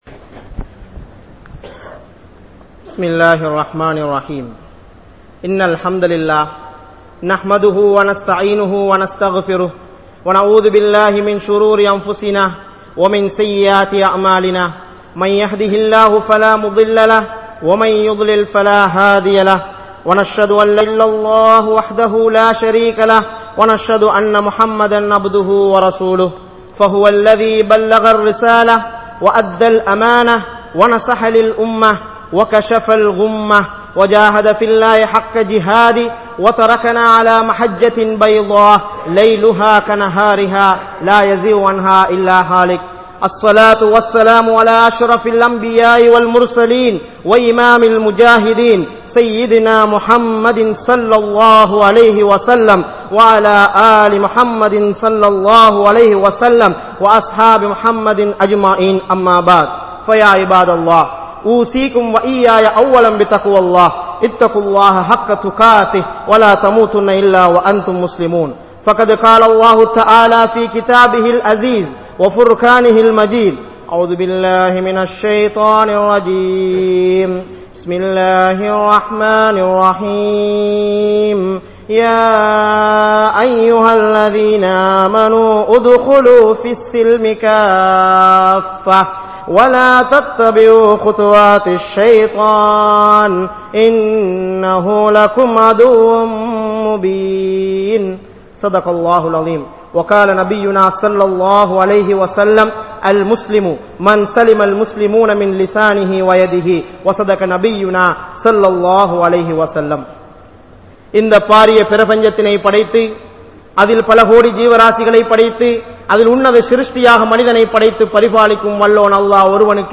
Indraya Muslimkal Maranthavaihal (இன்றைய முஸ்லிம்கள் மறந்தவைகள்) | Audio Bayans | All Ceylon Muslim Youth Community | Addalaichenai
Grand Jumua Masjith